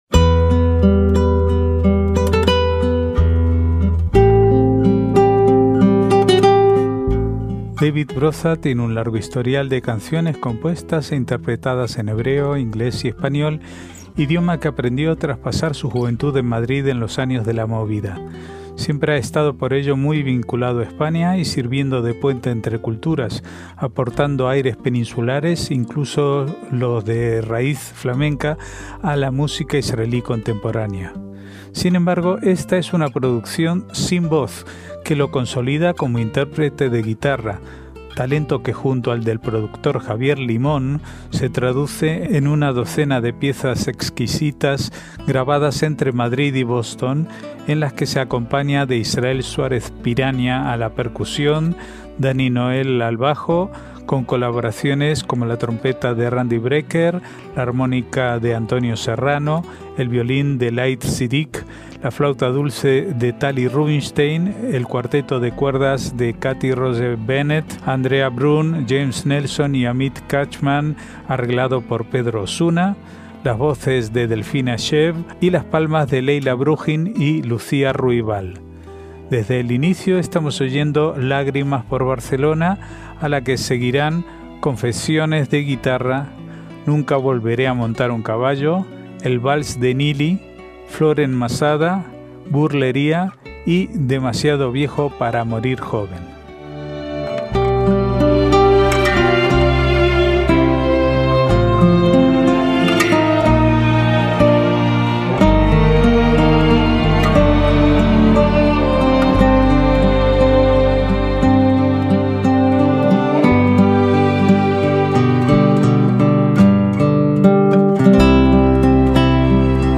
esta es una producción sin voz
grabadas entre Madrid y Boston
percusión
bajo